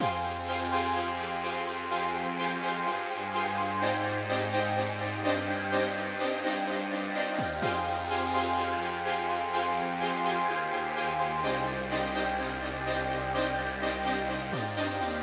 基本陷阱节拍 鼓
Tag: 126 bpm Trap Loops Drum Loops 2.56 MB wav Key : C